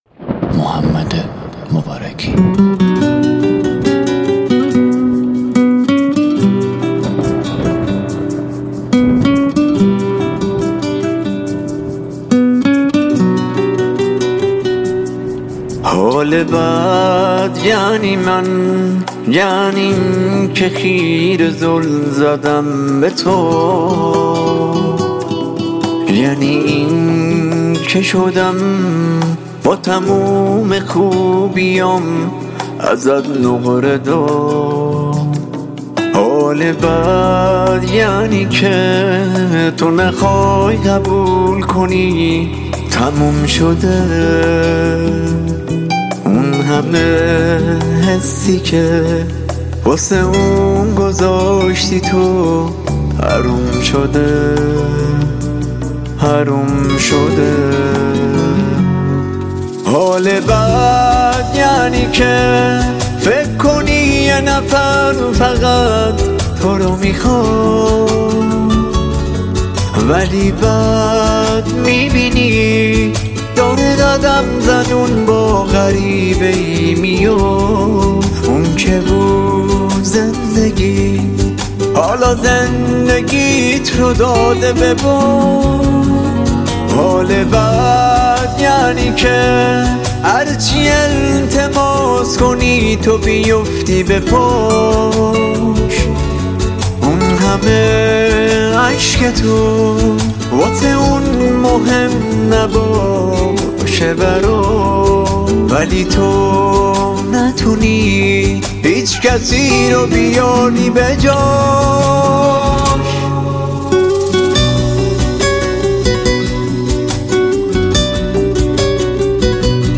در سبک پاپ
غمگین